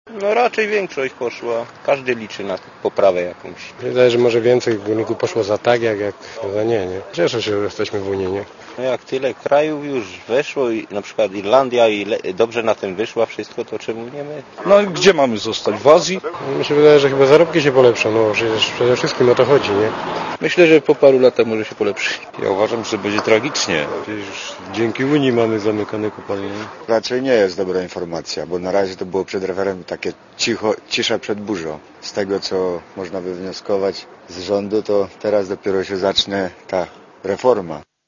Górnicy
gornicy.mp3